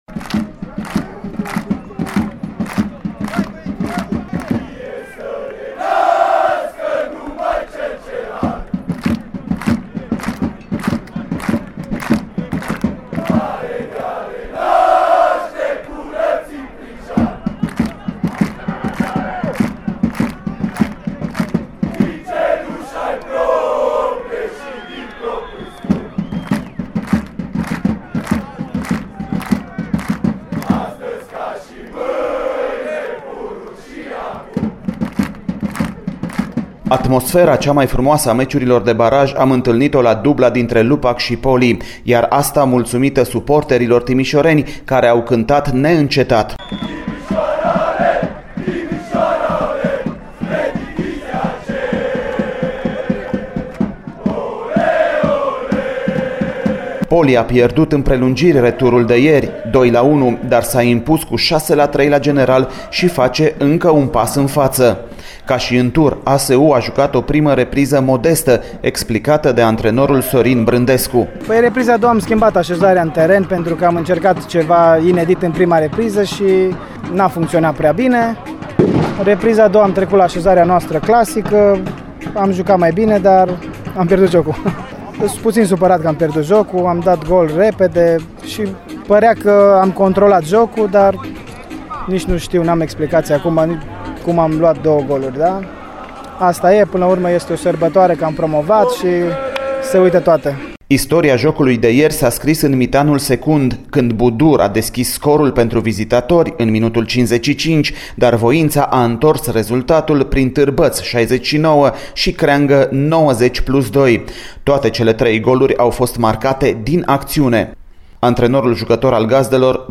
AUDIOREPORTAJ / Fericiți, și unii și alții
Atmosfera cea mai frumoasă a meciurilor de baraj am întâlnit-o la dubla dintre Lupac și Poli, iar asta mulțumită suporterilor timișoreni care au cântat neîncetat.